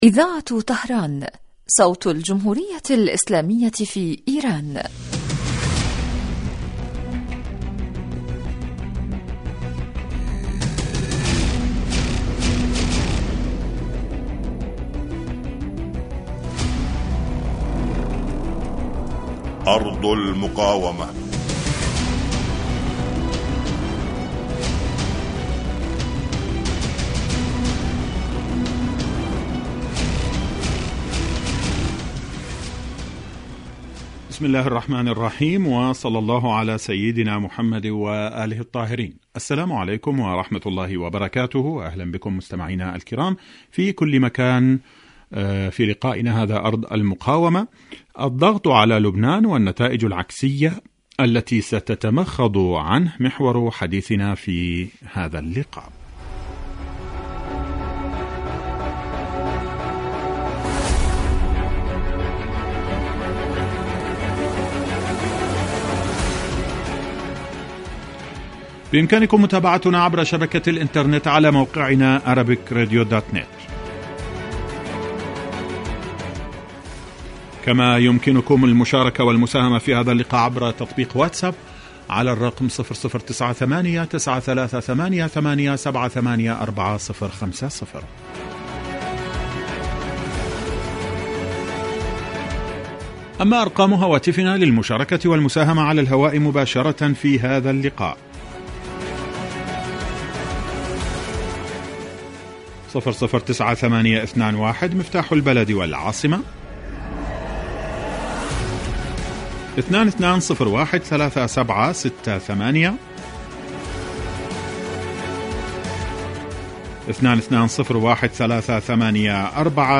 برنامج إذاعي حي لنصف ساعة يتناول بالدراسة والتحليل آخر التطورات والمستجدات على صعيد سوريا والأردن وفلسطين المحتلة ولبنان.
يستهل المقدم البرنامج بمقدمة يعرض فيها أهم ملف الأسبوع ثم يوجه تساؤلاته إلى الخبراء السياسيين الملمين بشؤون وقضايا تلك الدول والذين تتم استضافتهم عبر الهاتف.